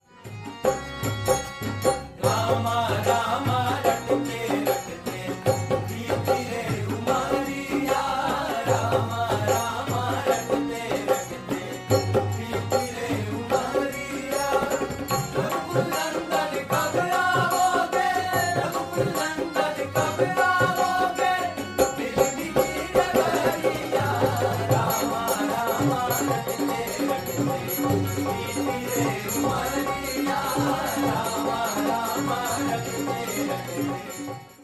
Ram Bhajan Ringtone